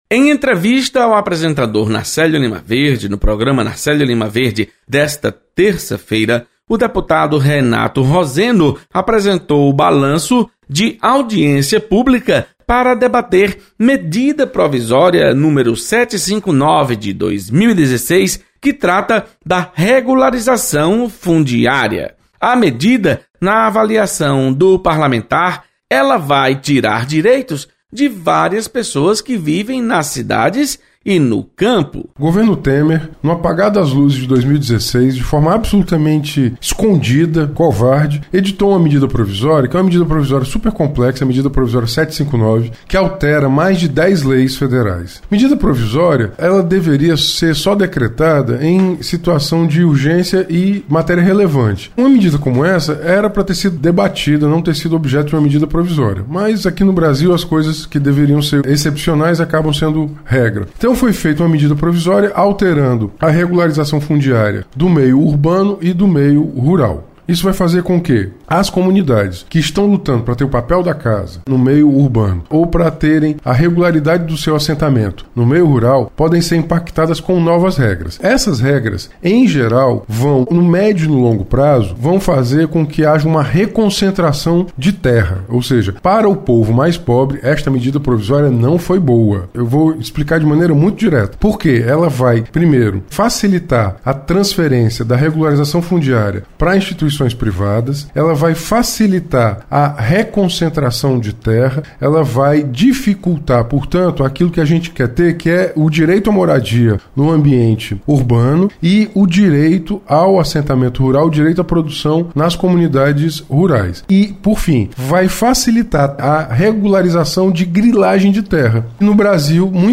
Entrevista
Deputado Renato Roseno comenta sobre resultado de Audiência pública para debater MP da Regularização Fundiária.